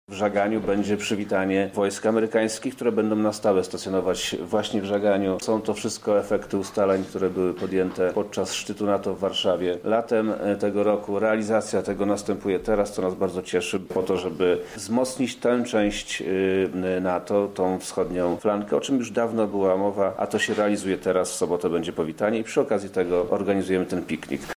Wzorując się na piknikach organizowanych podczas letniego szczytu NATO w Warszawie, Ministerstwo Obrony Narodowej zaplanowało takie uroczystości w każdym mieście wojewódzkim. O całej idei mówi Wojewoda Lubelski Przemysław Czarnek: